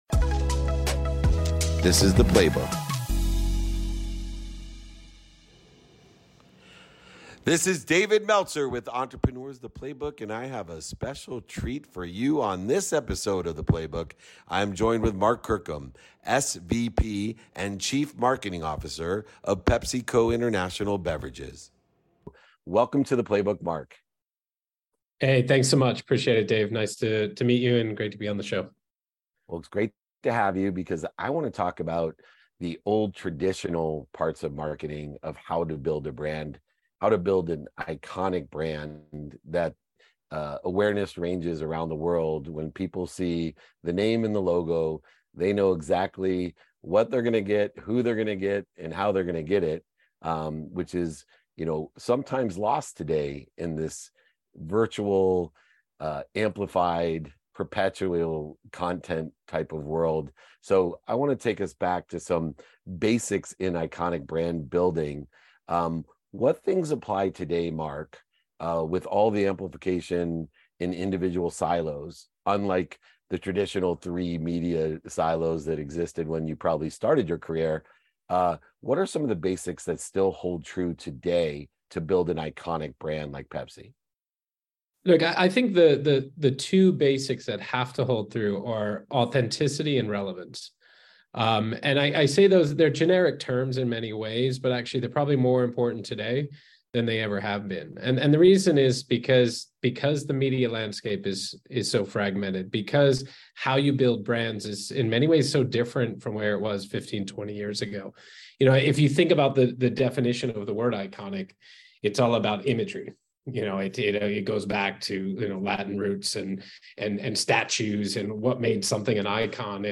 Today’s episode is a conversation